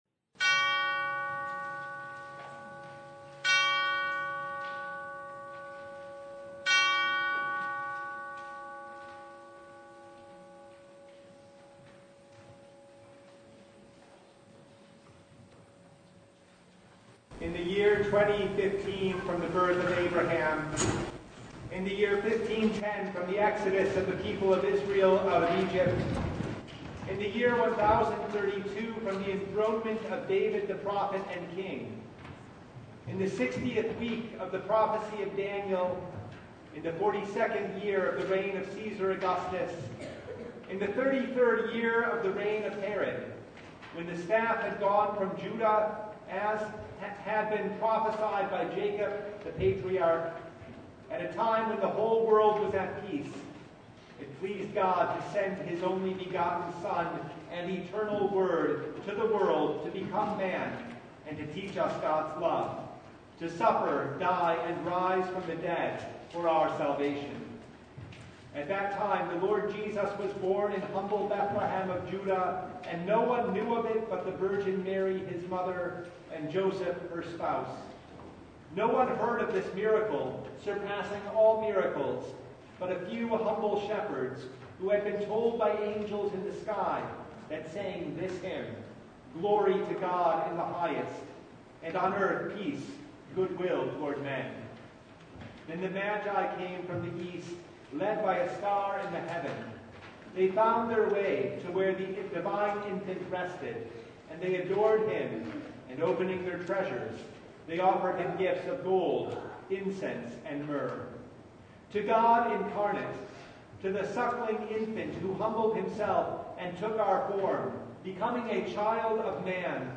Service Type: Christmas Day
Full Service